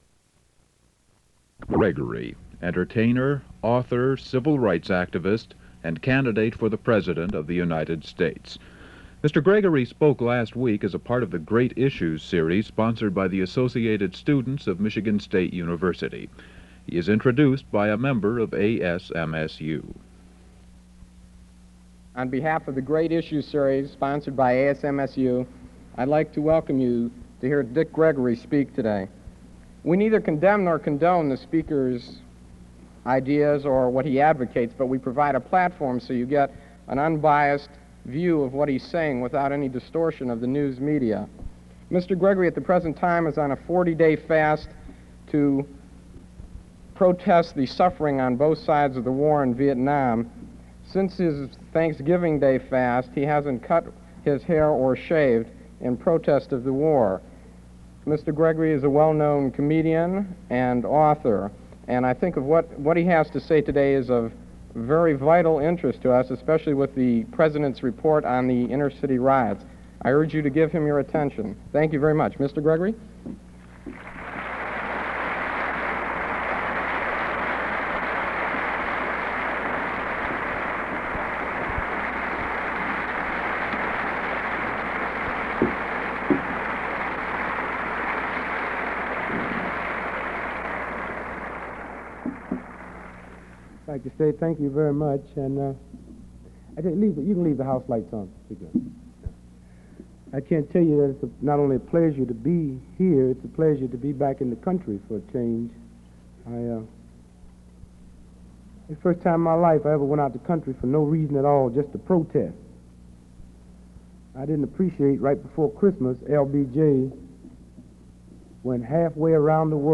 Dick Gregory Speaks at Great Issues Lecture Series, 1968 Back Subjects: Students, Campus, Vietnam, Events Description: Dick Gregory speaks to about 1,500 students at Michigan State University Auditorium, in a Great Issues lecture sponsored by the Associated Students of Michigan State University (ASMSU). Topics include Vietnam, U.S. President Lyndon Johnson, the Detroit riots, Stokely Carmichael, H. Rap Brown, Gregory's plans to disrupt the August 1968 national convention in Chicago, and his general distrust of politicians. Date: March 8, 1968 Format: Audio/mp3 Original Format: Open reel audio tape Resource Identifier: A008269 Collection Number: UA 17.200 Language: English Rights Management: Educational use only, no other permissions given.